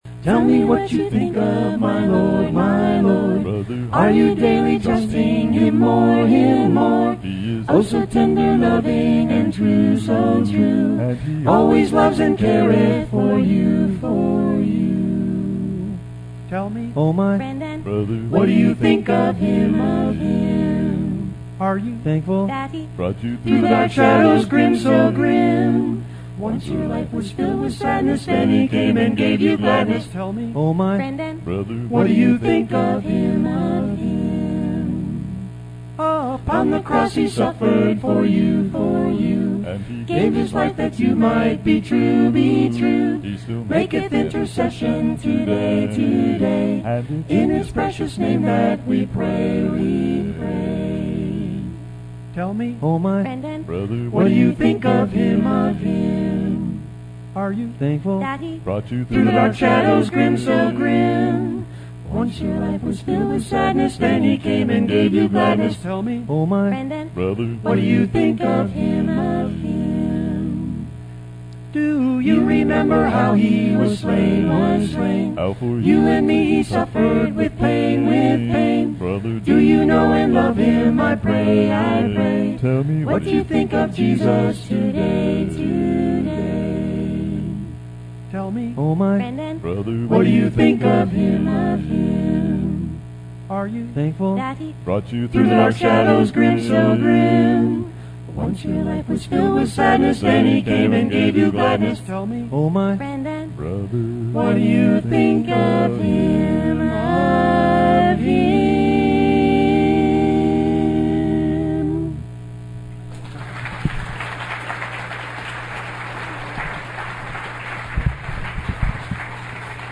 Our Lord Christ Jesus: The Wisdom of God (Family Camp 2017) – Part 13 August 3, 2017 Teaching 13 in a series of 14 teachings on some of the many things God has declared Jesus Christ to be to us and for us.